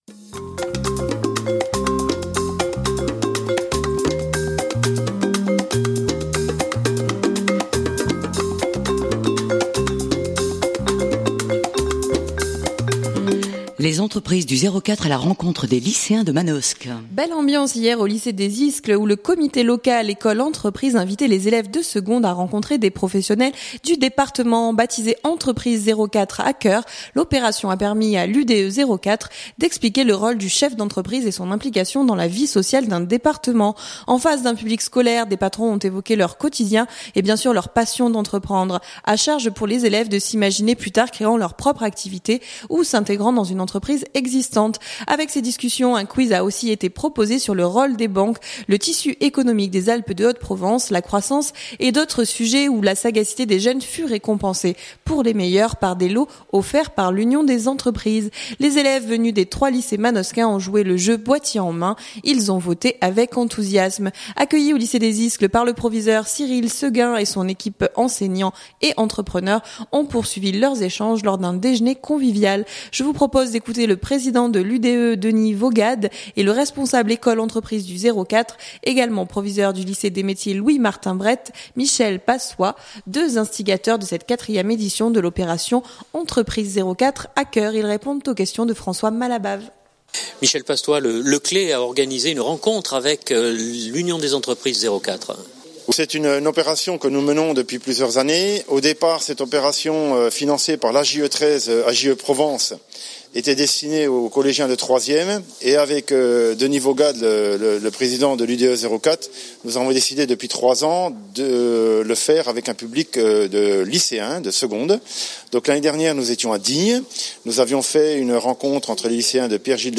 Ils répondent aux questions